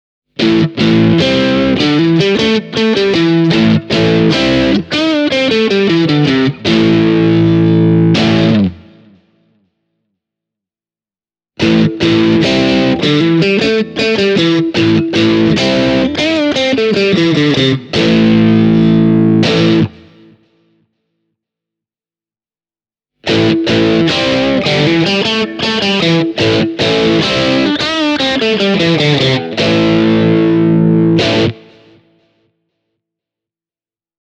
Myös särösoundeissa mikrofonien puolitus suo soittajalle mahdollisuuden nostaa välillä kaasujalkaa polkimelta: